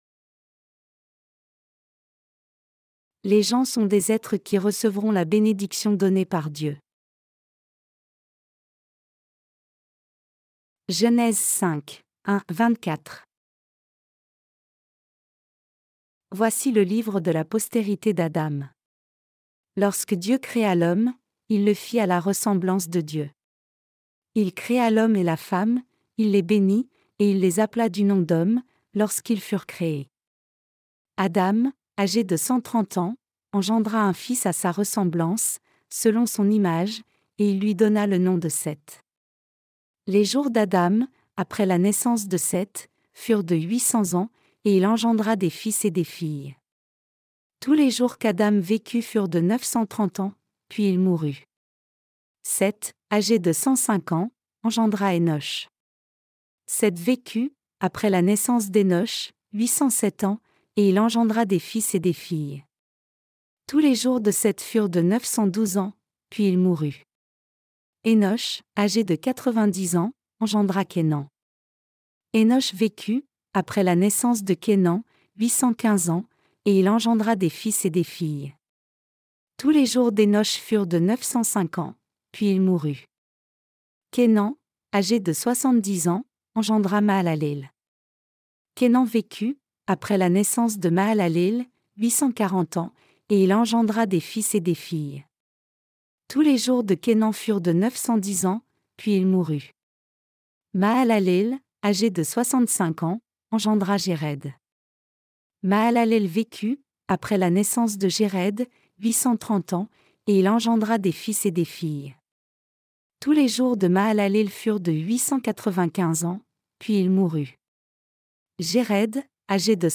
Sermons sur la Genèse (V) - LA DIFFERENCE ENTRE LA FOI D’ABEL ET LA FOI DE CAÏN 8.